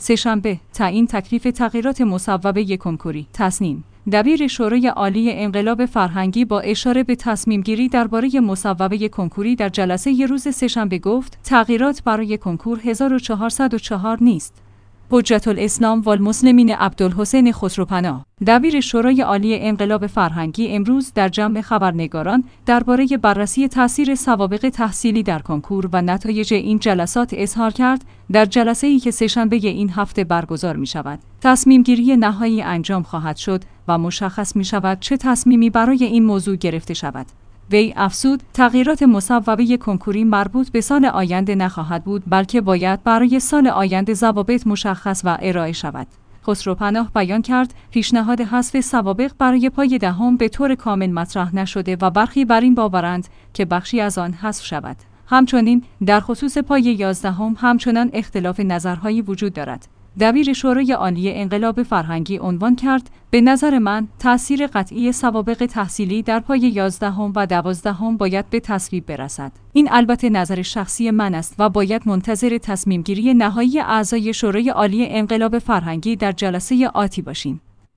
حجت‌الاسلام والمسلمین عبدالحسین خسروپناه؛ دبیر شورای عالی انقلاب فرهنگی امروز در جمع خبرنگاران درباره بررسی تأثیر سوابق تحصیلی در کنکور و نتایج این جلسات اظهار کرد: